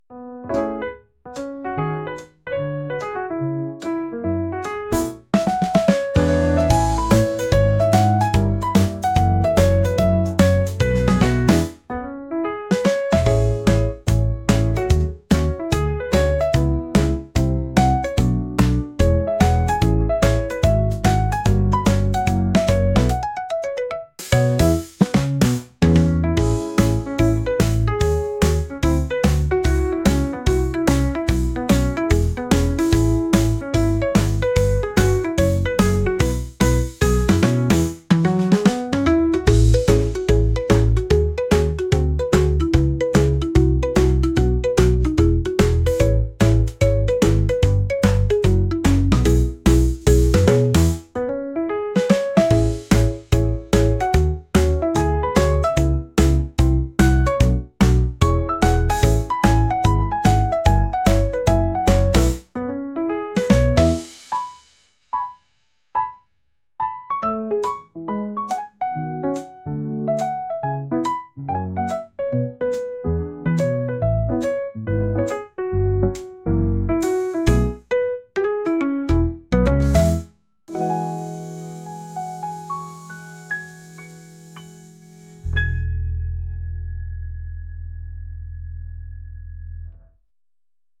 ゆったりすごすジャズのピアノ曲です。